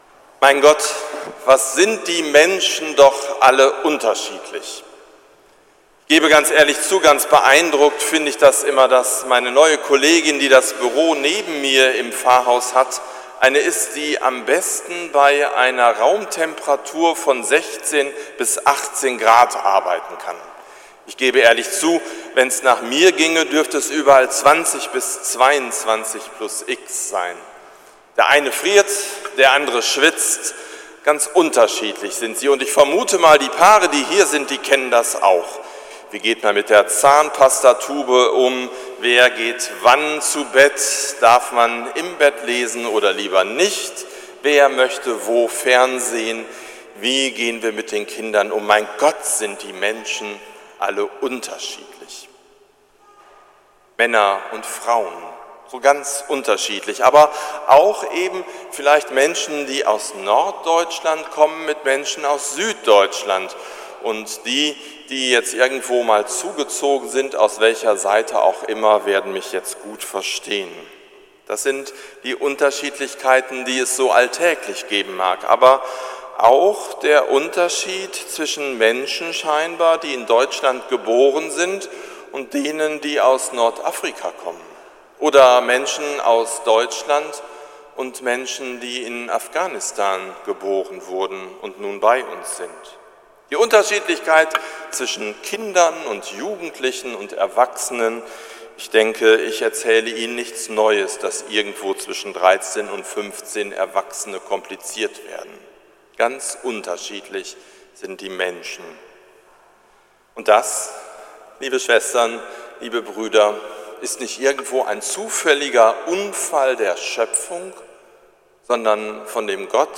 Predigt zu Pfingsten 2017 – St. Nikolaus Münster
predigt-zu-pfingsten-2017